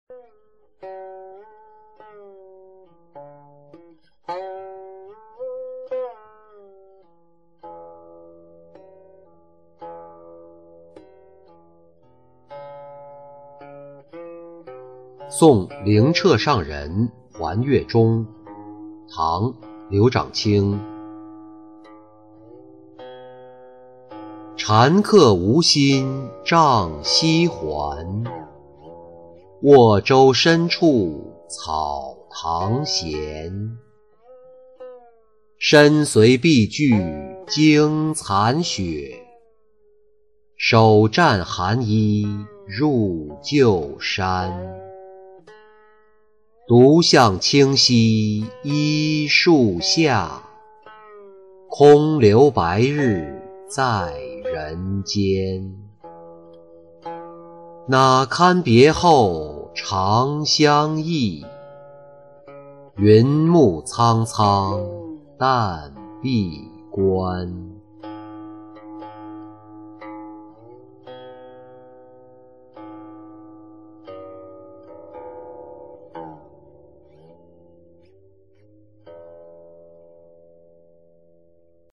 送灵澈上人还越中-音频朗读